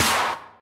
soft-hitfinish222.ogg